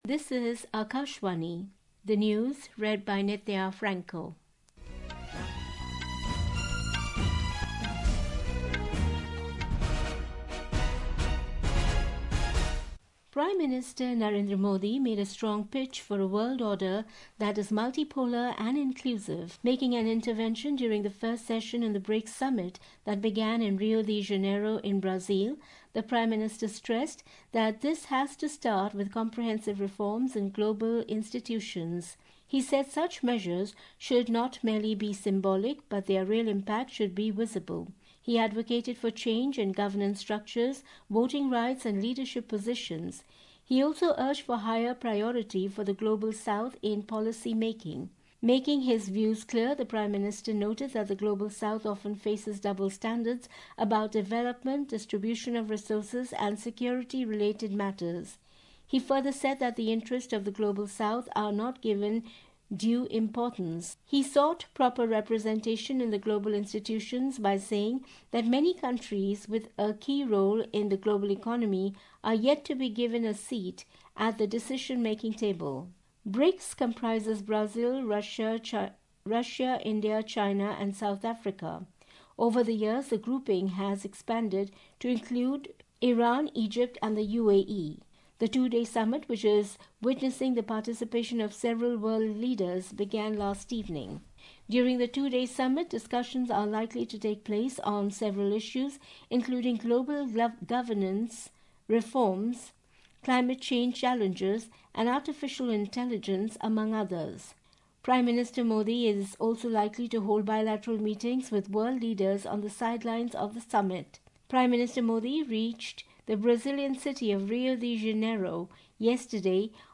Hourly News
Hourly News | English